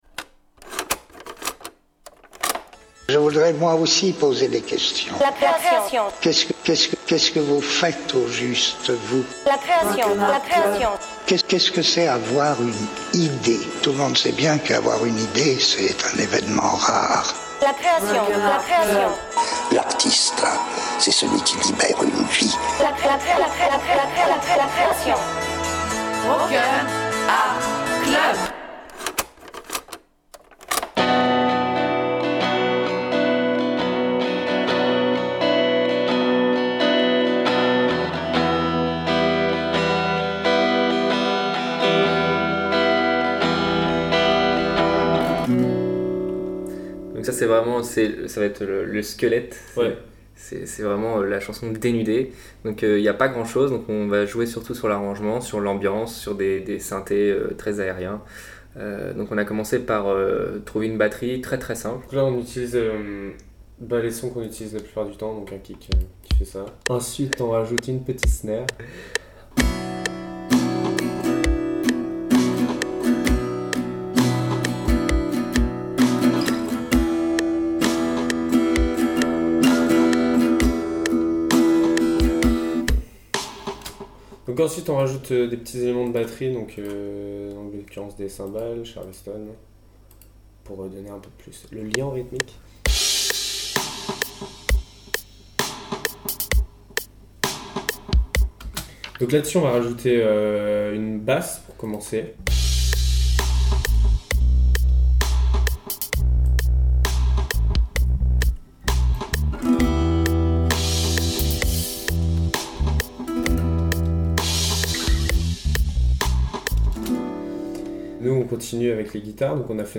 Au commencement, une mélodie de guitare, puis claviers aériens et guitares apocalyptiques font leur entrée. Une voix, deux trois mots : un morceau.